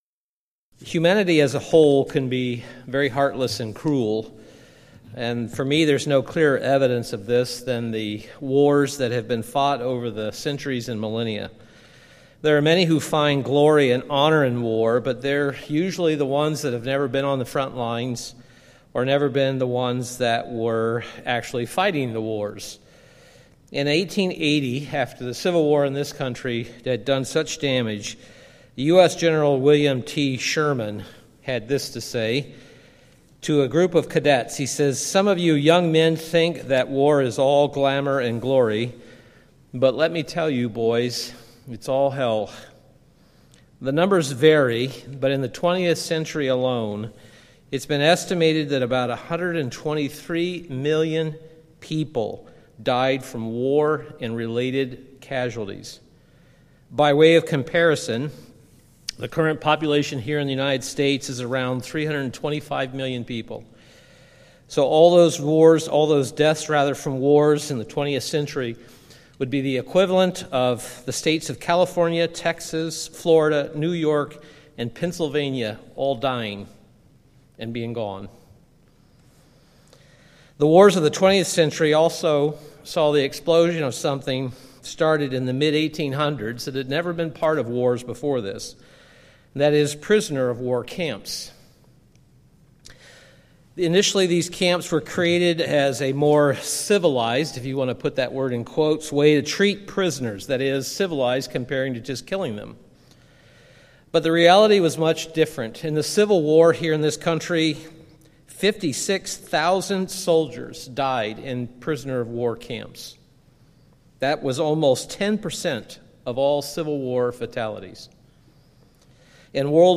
Given in Milwaukee, WI
UCG Sermon